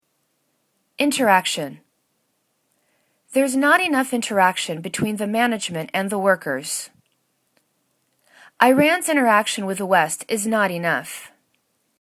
in.ter.ac.tion    /intәr'akshәn/    n